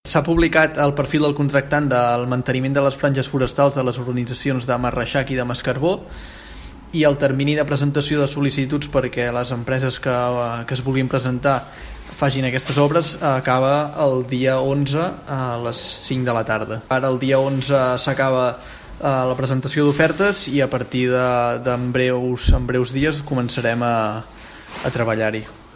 En parla Aleix Freixa, regidor de Medi Ambient de l’Ajuntament de Palafolls.